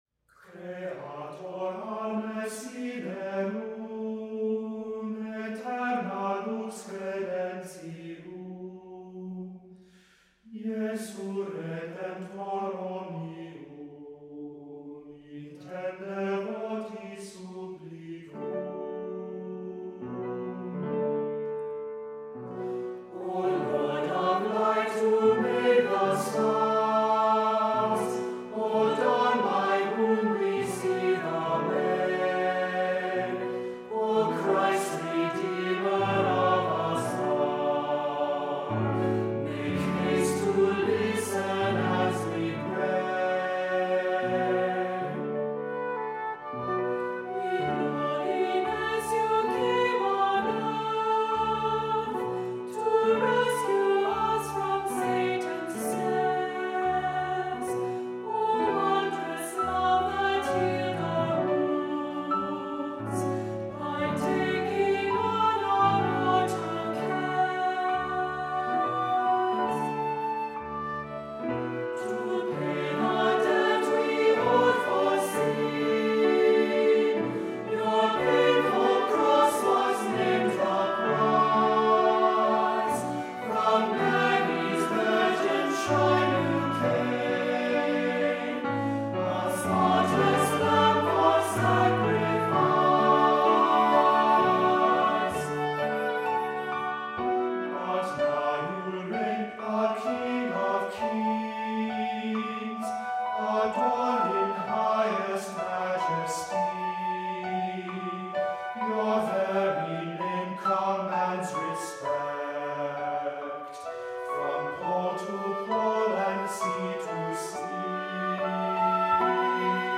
Voicing: Descant,SATB